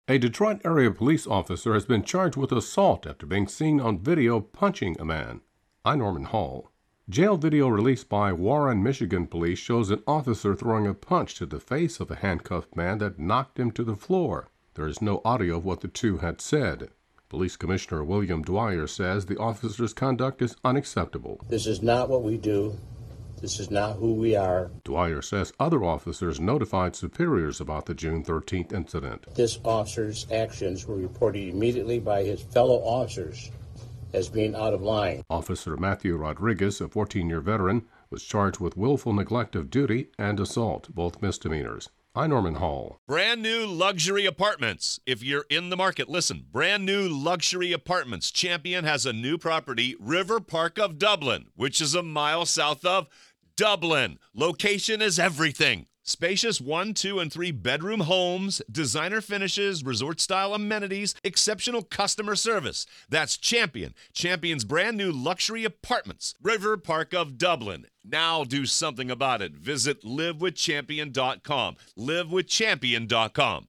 AP correspondent reports